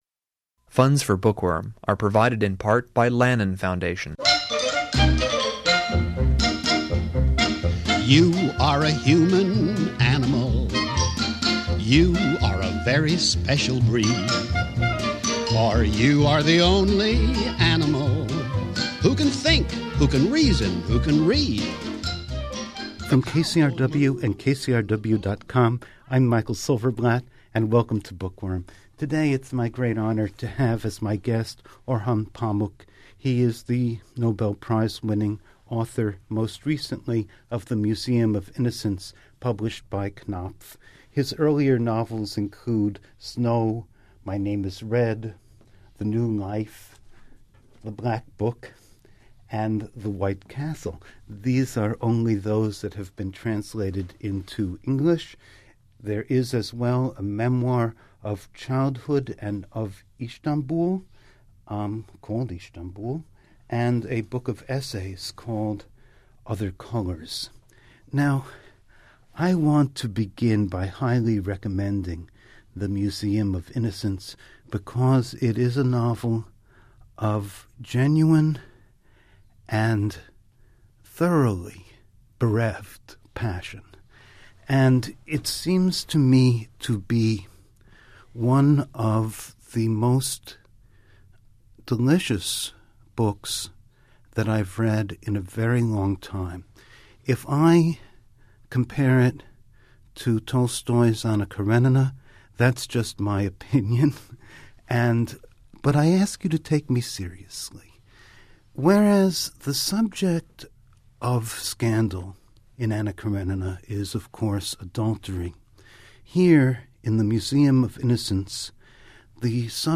Bookworm Orhan Pamuk, Part I The Museum of Innocence (Knopf) Infidelity and adultery are two of the great subjects of the novel tradition — think of Anna Karenina or Madam Bovary. In this conversation, Turkish Nobel Prize winner Orhan Pamuk discusses his own stunning contribution to this tradition.